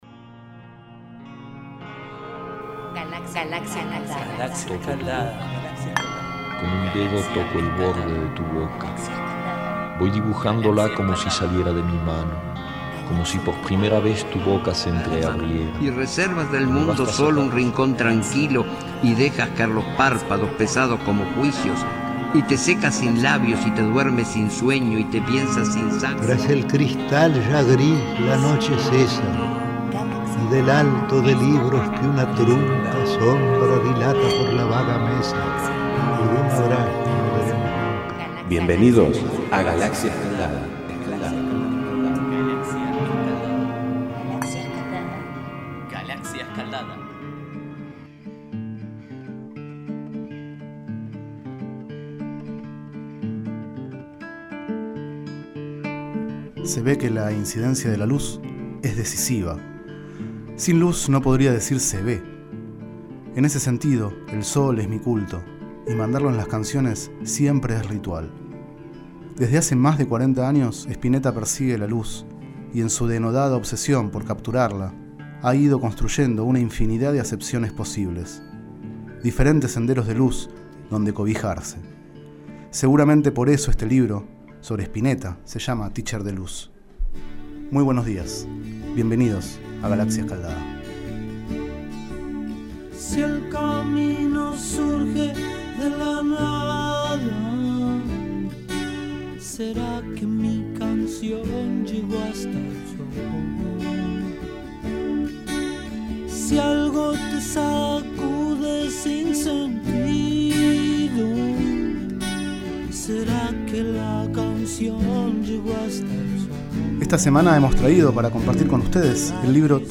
Este es el 33º micro radial, emitido en los programas Enredados, de la Red de Cultura de Boedo, y En Ayunas, el mañanero de Boedo, por FMBoedo, realizado el 27 de octubre de 2012, sobre el libro Tícher de luz, de Migel Ángel Dente.